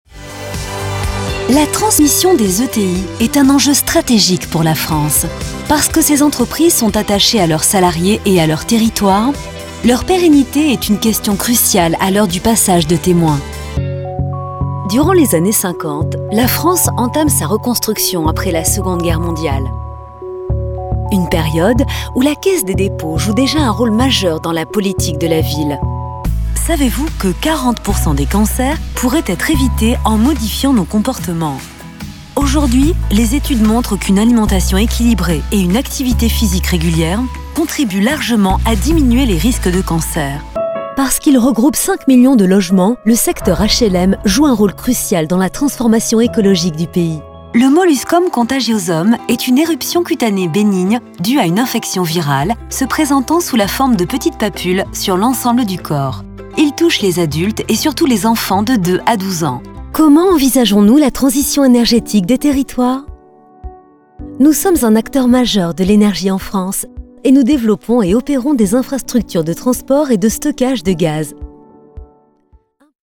Comercial, Natural, Travieso, Versátil, Empresarial
Corporativo